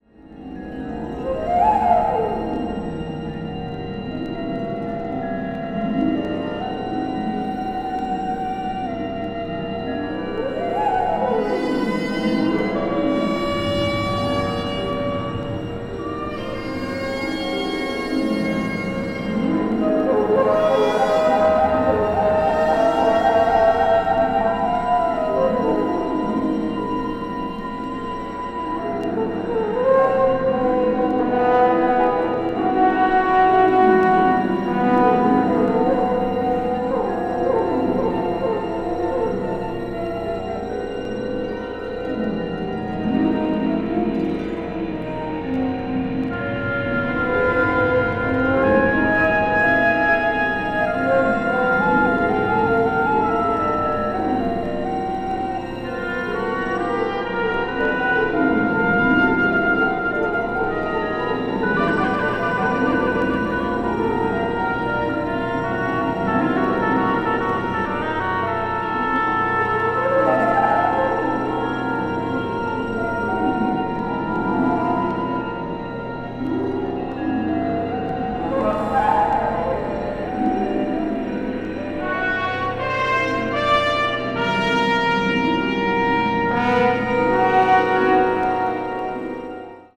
media : EX/EX(わずかにチリノイズが入る箇所あり)
シンセサイザーのような音も聞こえてきます。